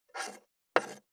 560まな板の上,包丁,ナイフ,調理音,料理,
効果音